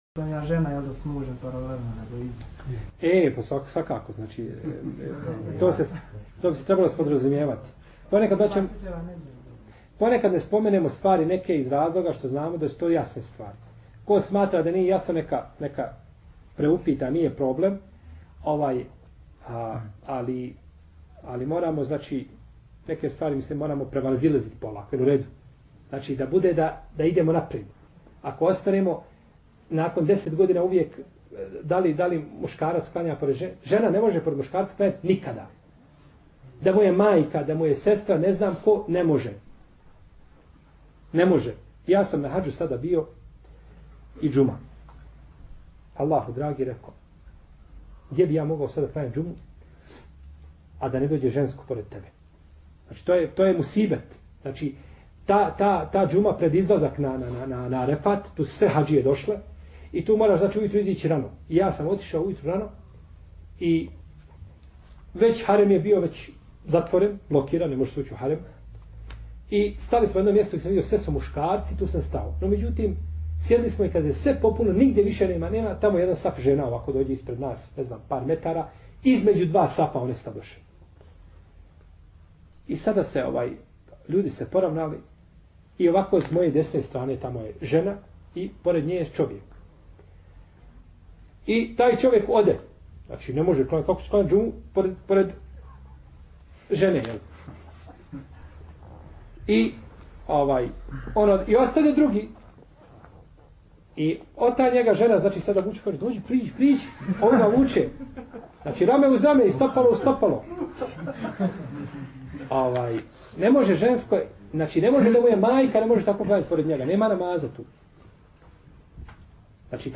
Poslušajte audio isječak odgovora